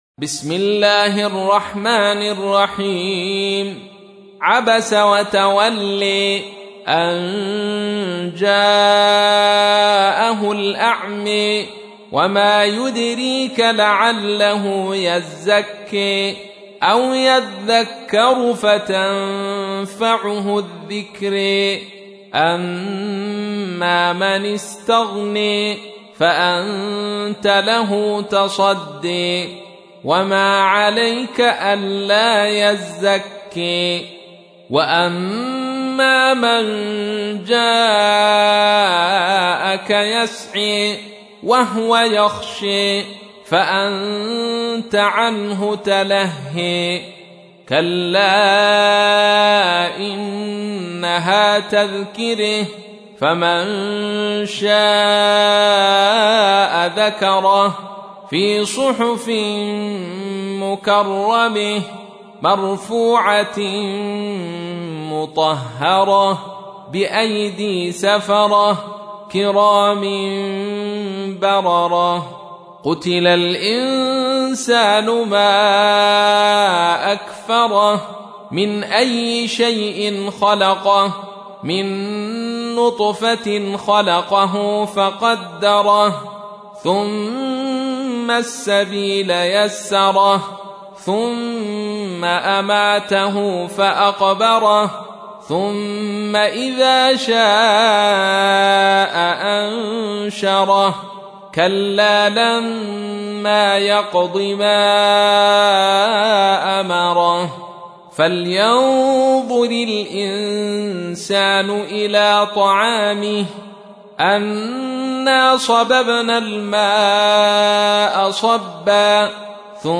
تحميل : 80. سورة عبس / القارئ عبد الرشيد صوفي / القرآن الكريم / موقع يا حسين